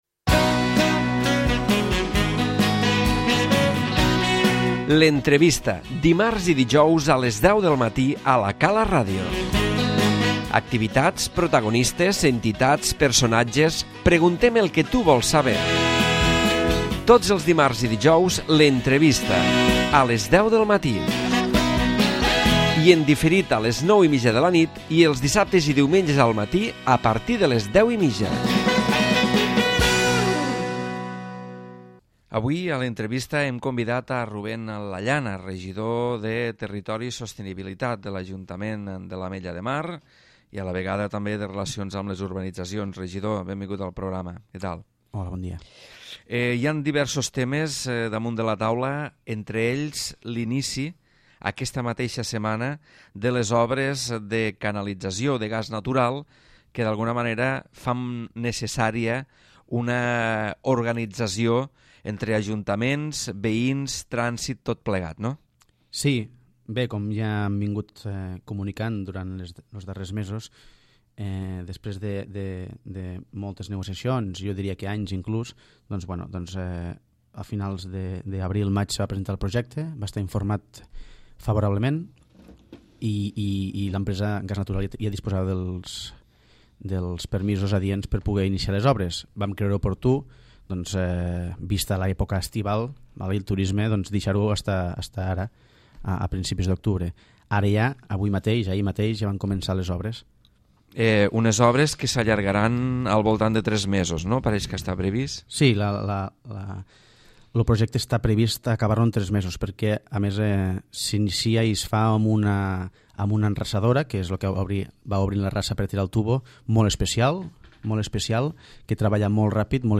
L'Entrevista
L'inici de les obres de Canalització de Gas Natural, és un dels temes que tracta Ruben Lallana, regidor de Territori i Sostenibilitat en aquesta entrevista, on a més surten temes com la fibra òptica, la cobertura wifi a les urbanitzacions, l'atenció al públic a les urbanitzacions o el Mercadona.